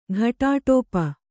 ♪ ghaṭāṭōpa